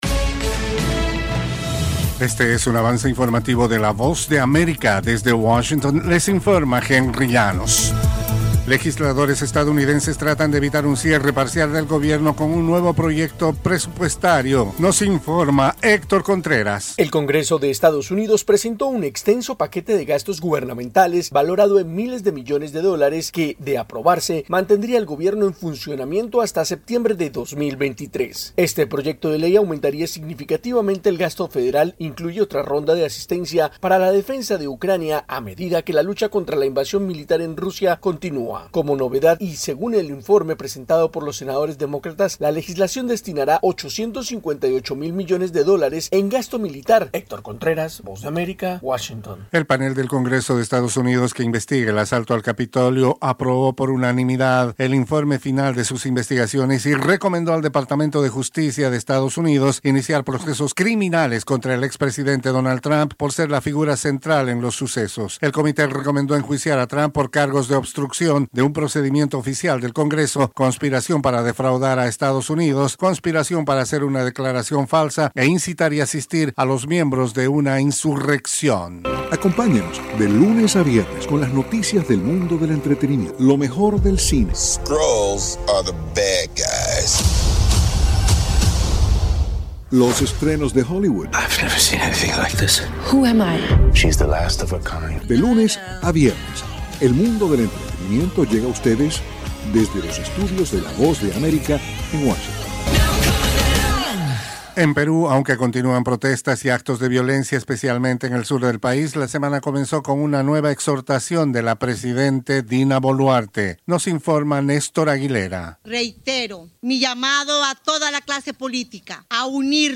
Cápsula informativa de tres minutos con el acontecer noticioso de Estados Unidos y el mundo. [10:00am Hora de Washington].